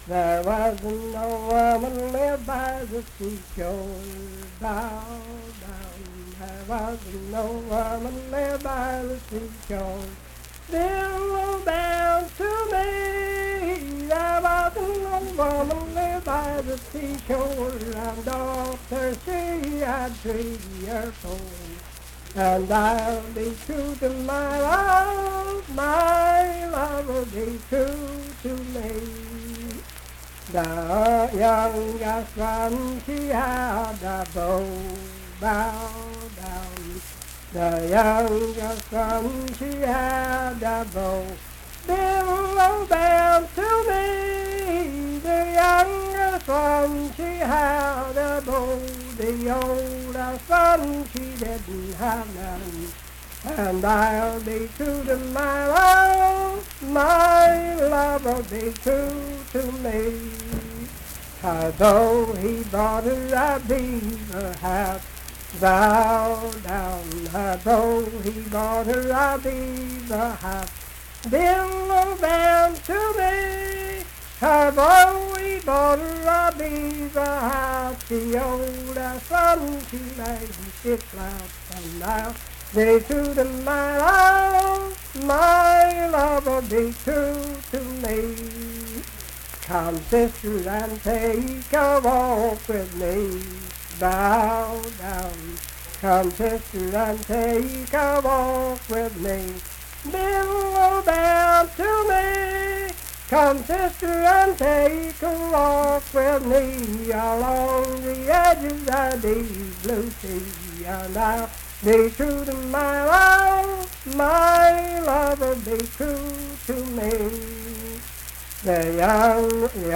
Unaccompanied vocal music
Verse-refrain 11d(4w/R).
Performed in Ivydale, Clay County, WV.
Voice (sung)